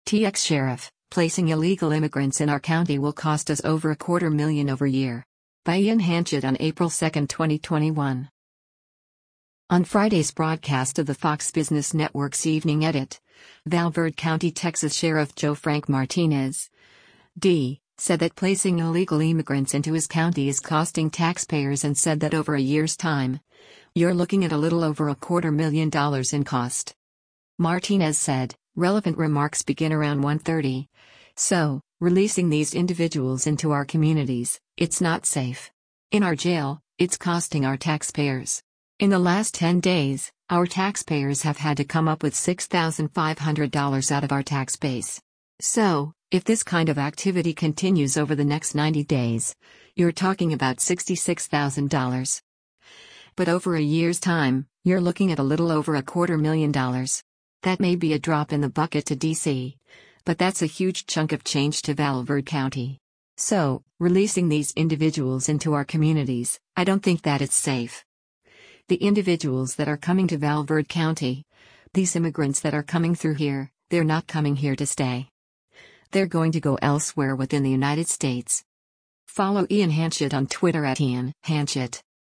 On Friday’s broadcast of the Fox Business Network’s “Evening Edit,” Val Verde County, TX Sheriff Joe Frank Martinez (D) said that placing illegal immigrants into his county is costing taxpayers and said that “over a year’s time, you’re looking at a little over a quarter-million dollars” in cost.